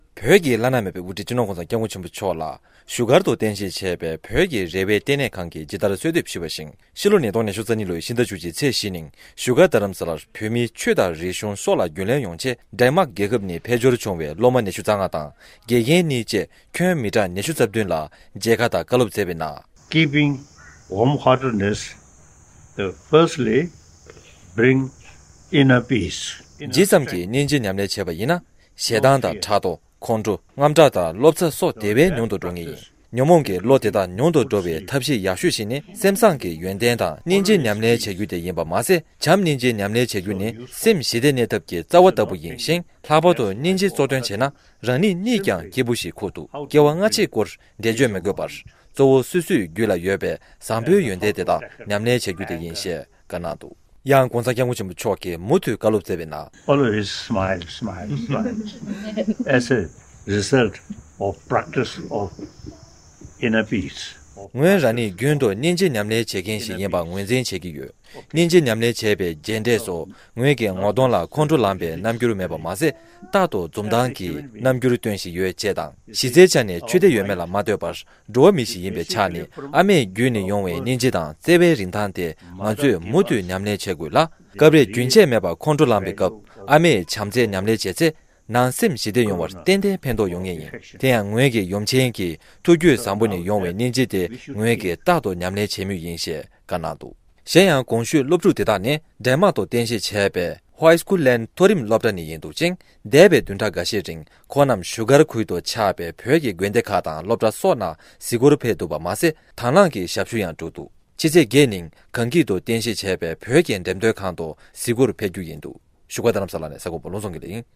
བྱམས་སྙིང་རྗེ་ཉམས་ལེན་བྱེད་རྒྱུ་ནི་སེམས་ཞི་བདེ་གནས་ཐབས་ཀྱི་རྩ་བ་ལྟ་བུ་ཡིན། ༧གོང་ས་མཆོག་གིས། ༧གོང་ས་མཆོག་གིས་ཌན་མརྐ་ནས་ཕེབས་པའི་སློབ་ཕྲུག་ཁག་ཅིག་ལ་བཀའ་བསྩལ་སྐབས། ༡༡།༠༤།༢༠༢༢
སྒྲ་ལྡན་གསར་འགྱུར། སྒྲ་ཕབ་ལེན།